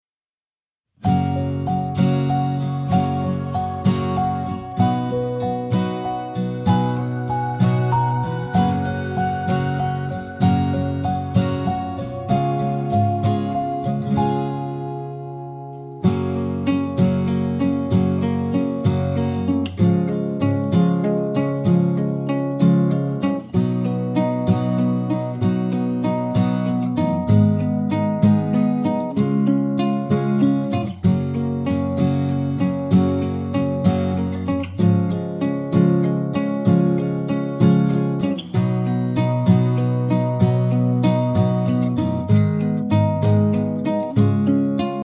Downloadable Instrumental Track